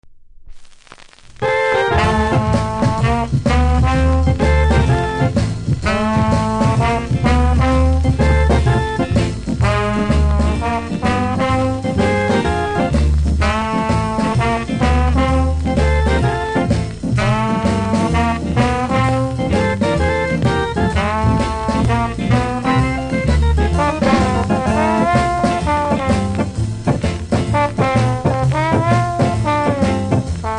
キズ、摩耗多めですが多少のノイズ気にしない人ならプレイ可レベル。